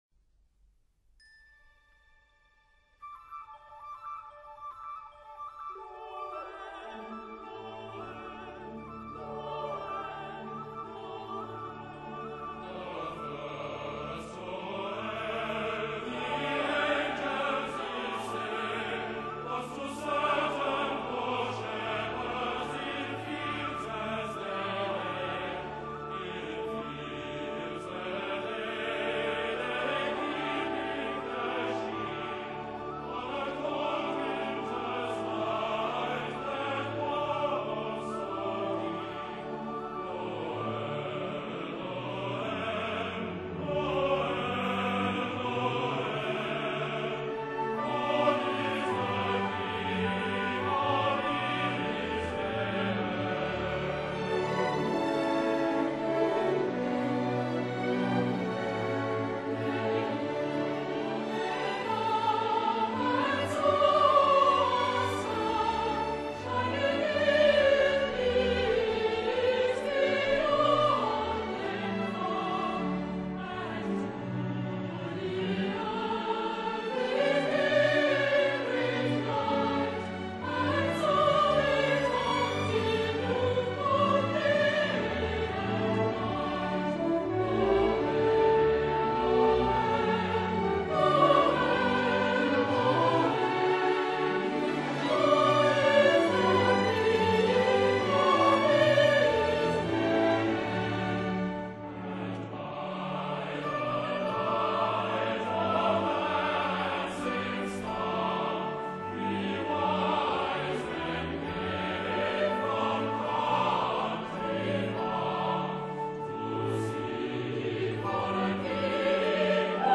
古典类型: 合唱
录音制作：1979-12 · Great Britain · ADD